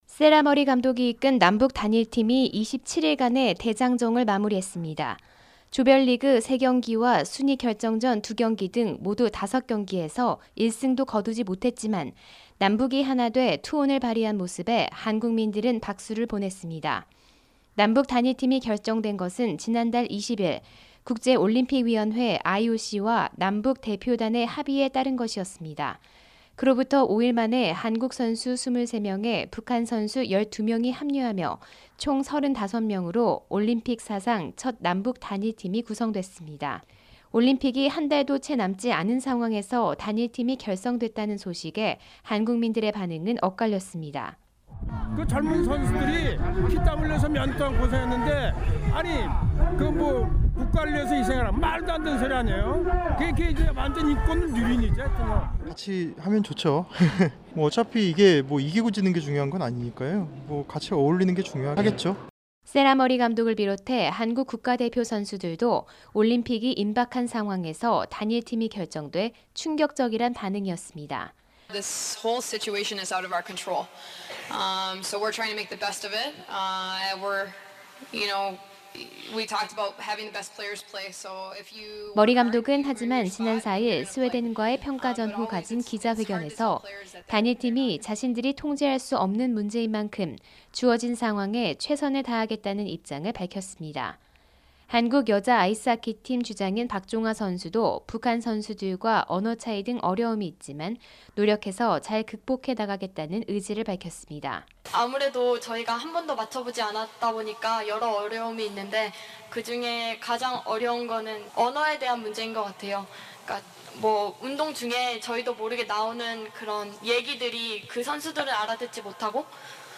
[특파원 리포트] 올림픽 첫 단일팀 대장정 마무리…머리 감독 “북한과 친선 교류전 논의 중”